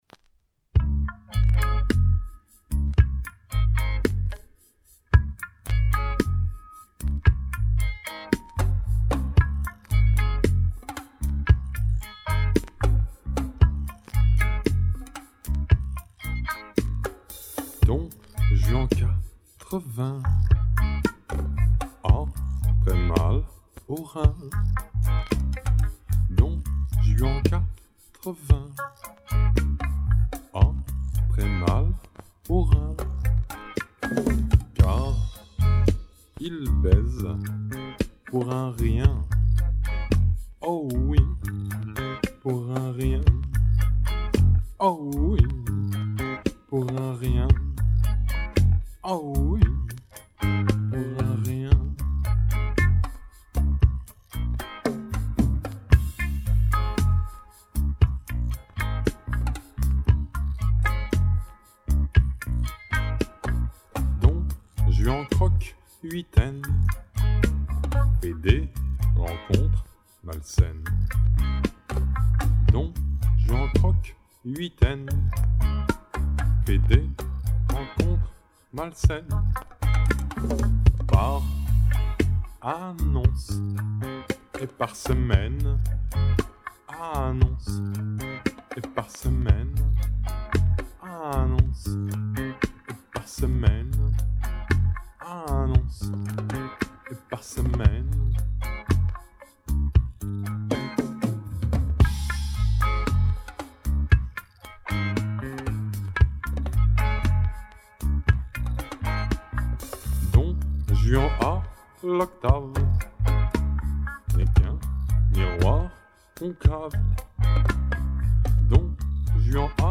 rythm guitar + acoustic guitars
Choeurs & Basse
Harmonica & Lead Guitar
Percussions & Batterie